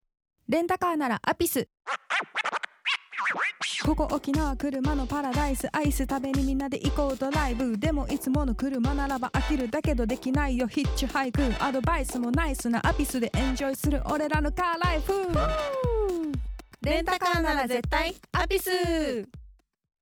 ラジオCM音源